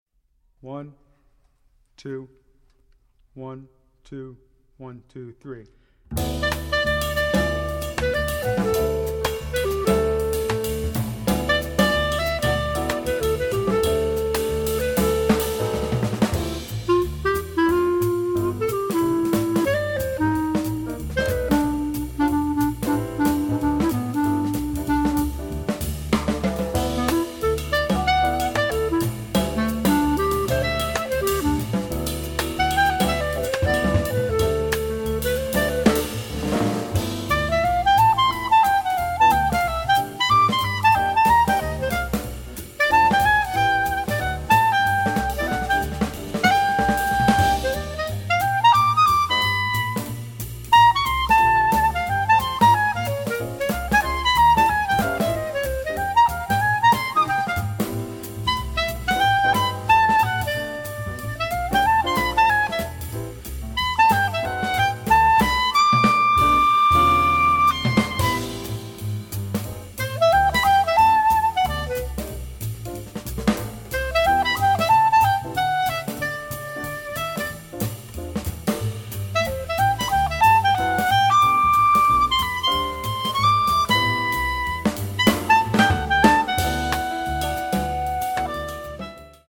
Voicing: Clarinet